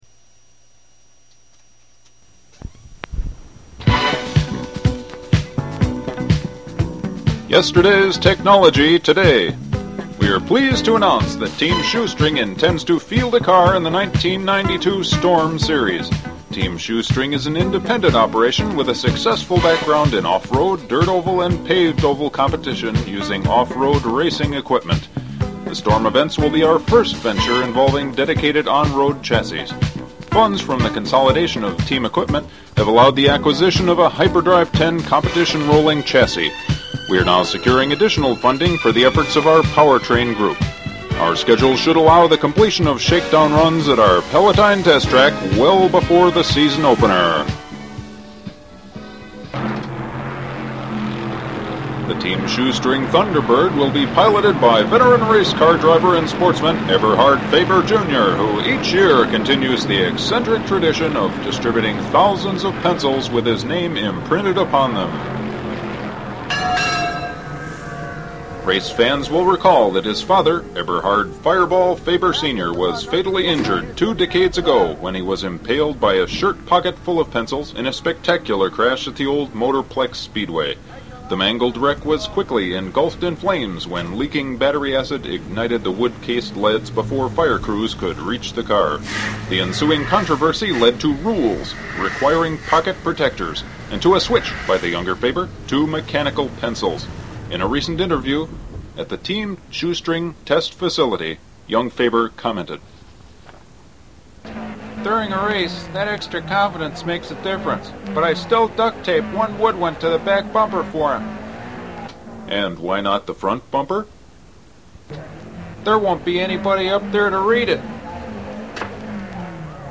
Unearthed after more than two decades in the Team Shoestring archive vaults, they are every bit as timely and as fascinating as they ever were!